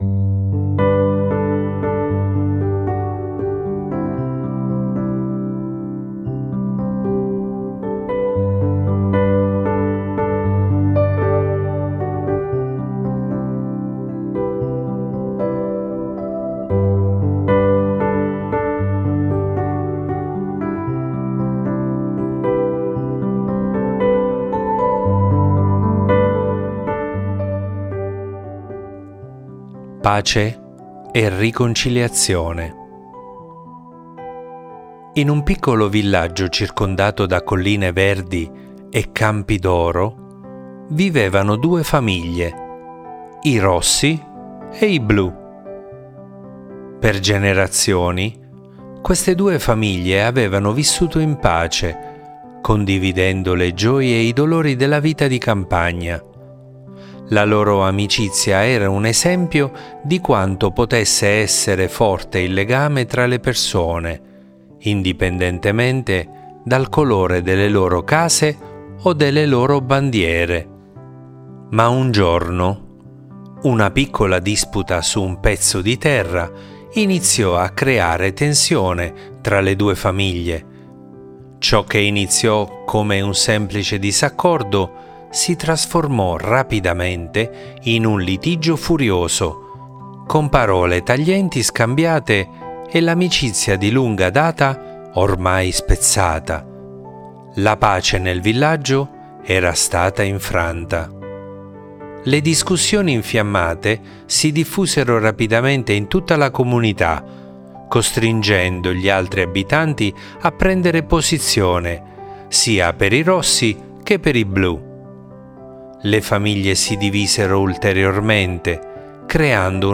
Leggi e ascolta la nostra storia che parla di pace e riconciliazione